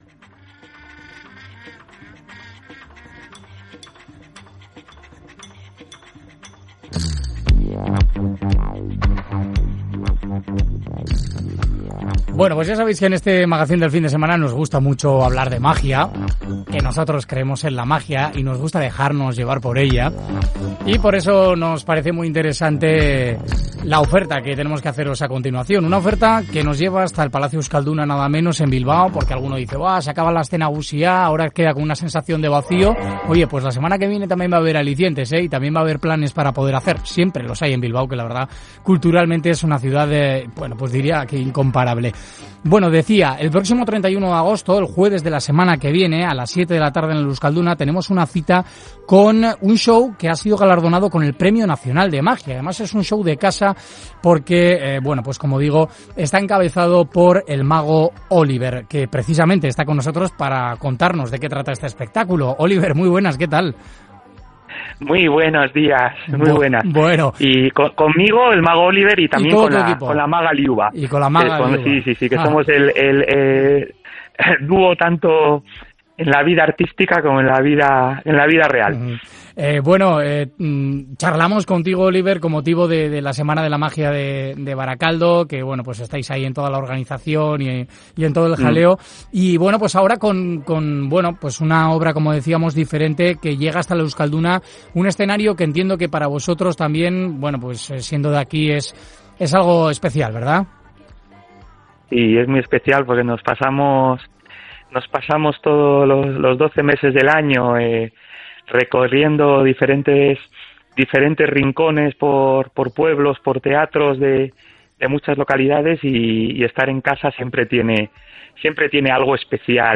Todas las Entrevistas